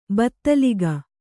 ♪ battaliga